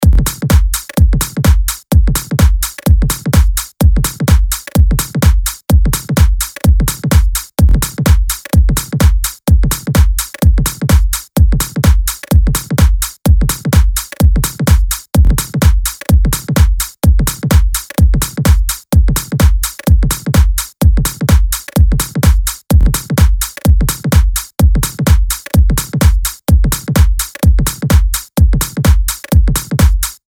LP 136 – DRUM LOOP – EDM – 127BPM